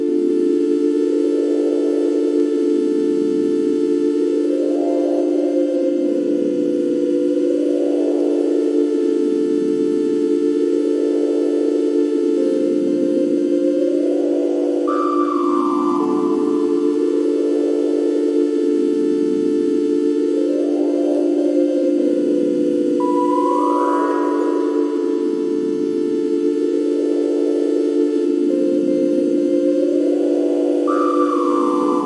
描述：一个合成器的纹理。
标签： 合成器 纹理
声道立体声